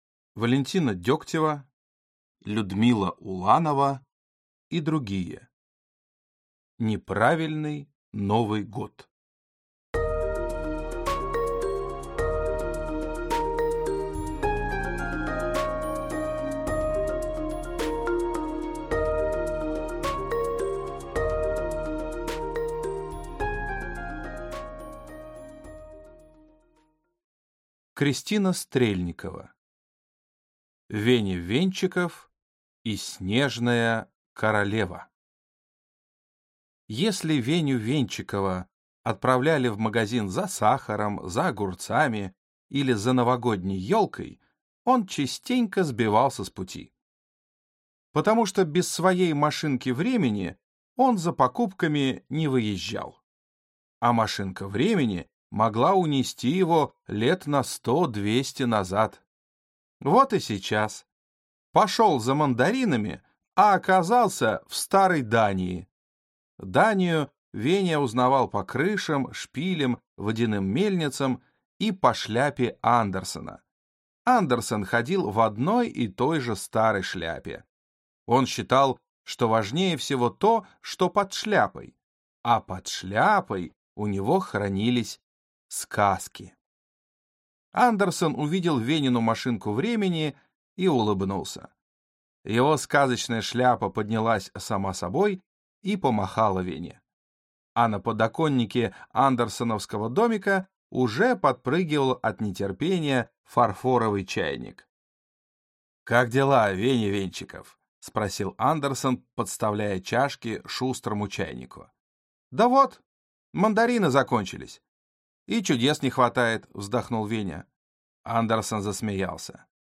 Аудиокнига Неправильный Новый год | Библиотека аудиокниг